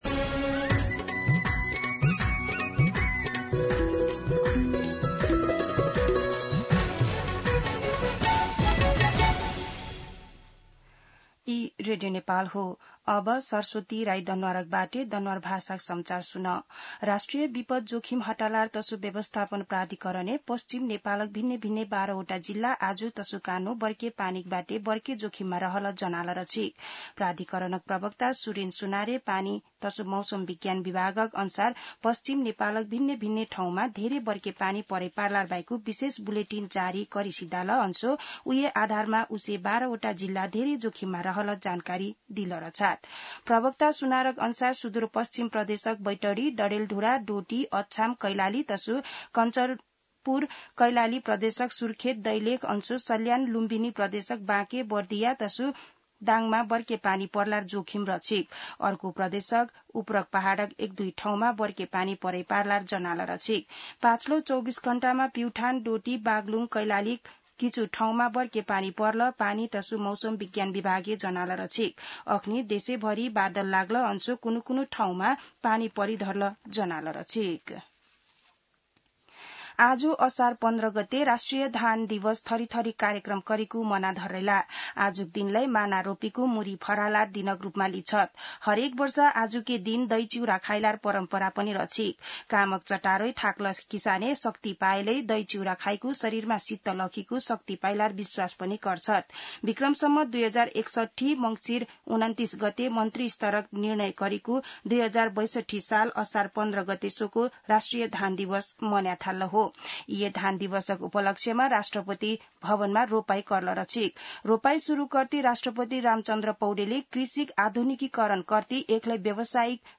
दनुवार भाषामा समाचार : १५ असार , २०८२
Danuwar-News-3-15.mp3